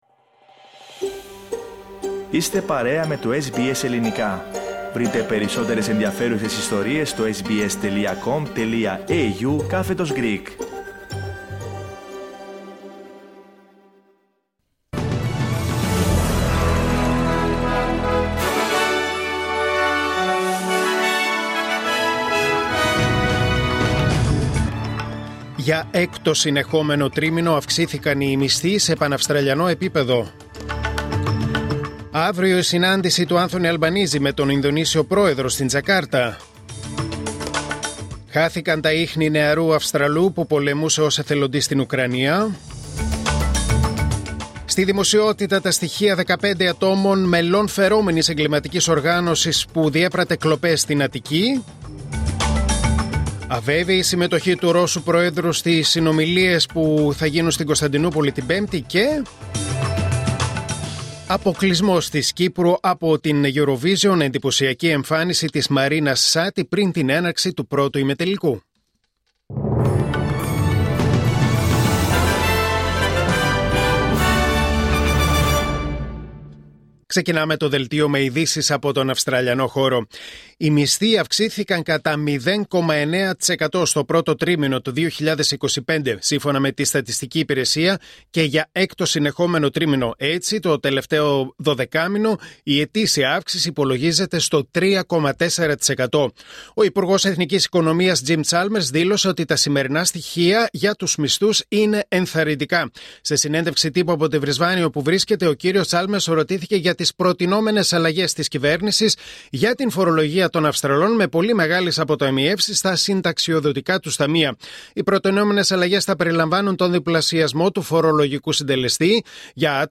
Δελτίο Ειδήσεων Τετάρτη 14 Μαΐου 2025